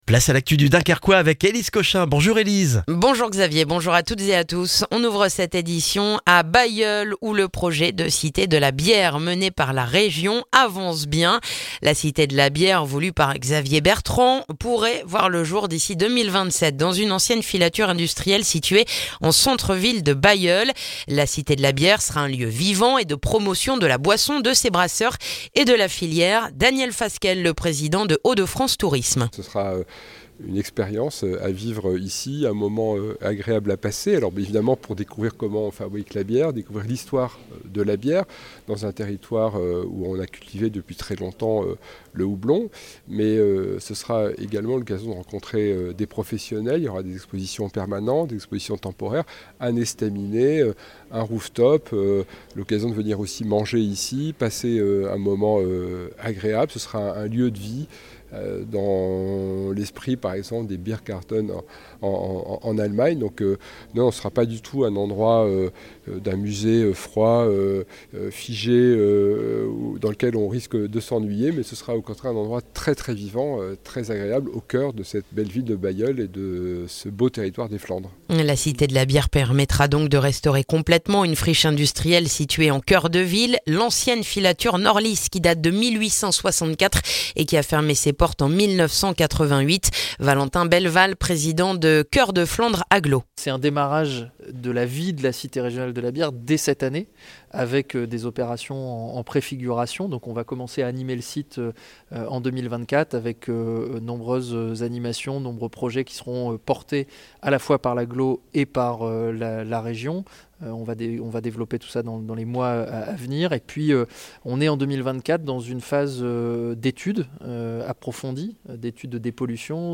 Le journal du mardi 6 février dans le dunkerquois